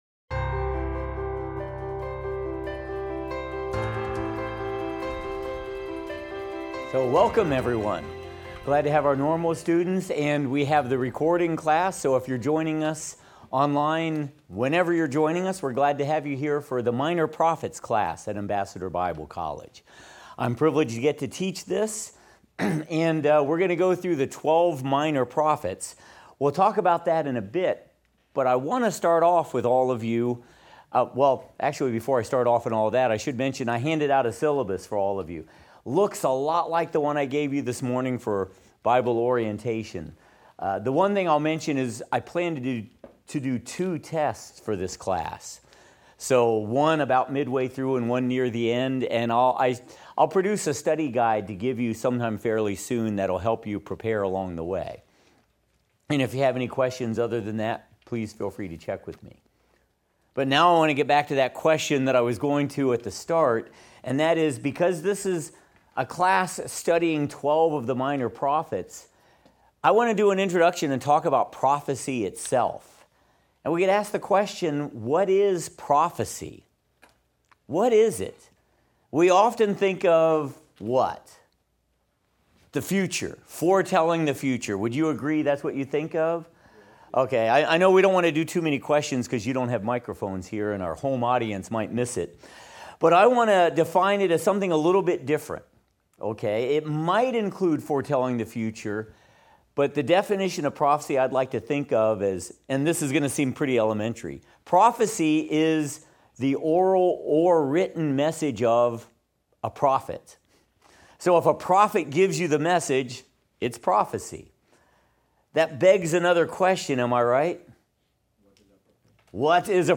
What is prophecy—and how can you tell a true prophet from a false one? In this opening Minor Prophets class, we lay the foundation from Scripture and begin Hosea’s powerful story of Israel’s unfaithfulness and God’s unfailing love.
Minor Prophets - Lecture 1 - audio.mp3